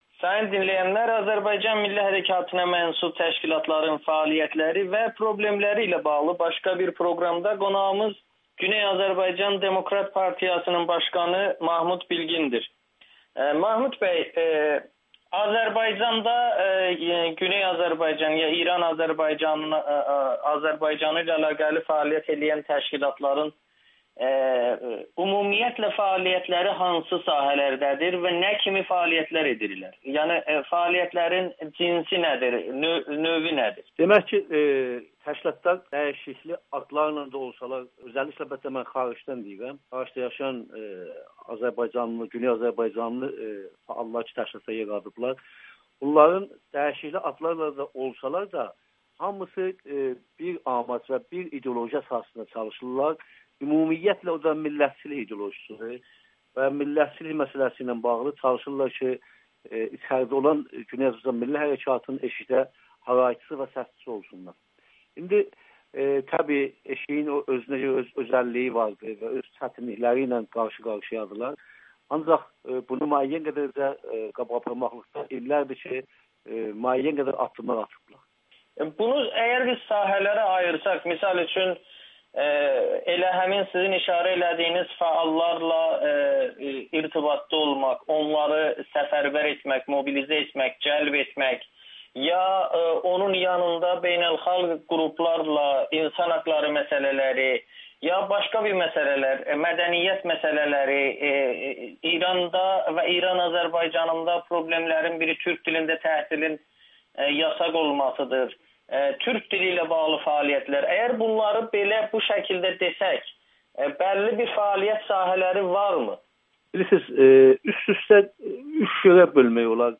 Xaricdəki milli fəalların 90 faizi fərdi fəaliyyətə üstünlük verir [Audio-Müsahibə]